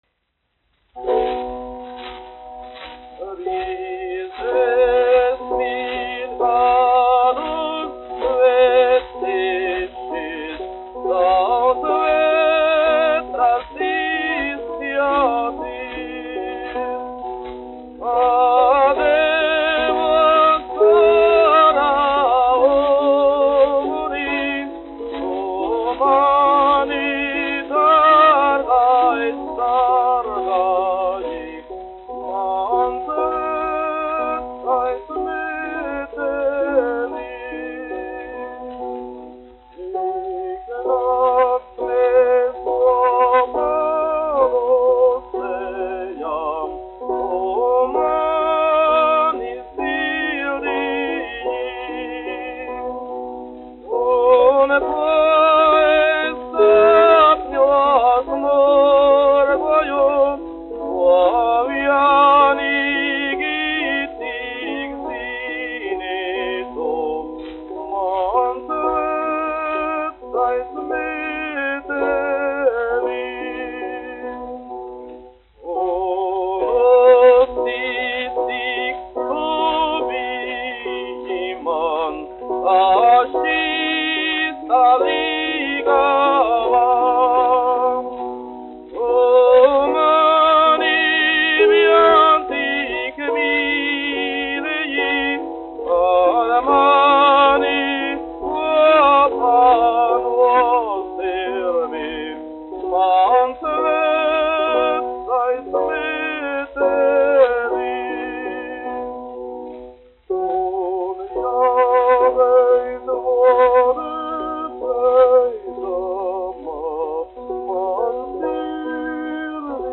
1 skpl. : analogs, 78 apgr/min, mono ; 25 cm
Dziesmas (vidēja balss) ar klavierēm
Skaņuplate